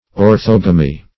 Search Result for " orthogamy" : The Collaborative International Dictionary of English v.0.48: orthogamy \or*thog"a*my\, n. [Ortho- + Gr.
orthogamy.mp3